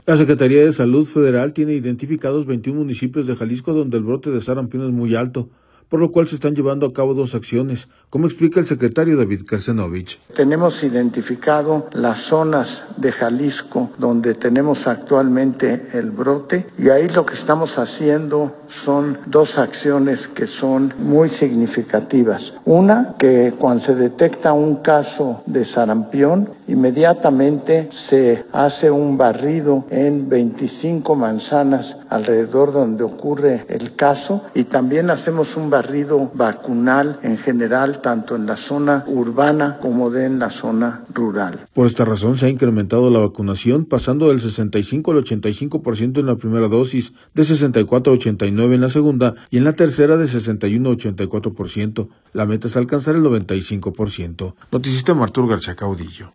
La Secretaría de Salud federal tiene identificados 21 municipios de Jalisco donde el brote de sarampión es muy alto, por lo cual se están llevando a cabo dos acciones, como explica el secretario David Kershenobich.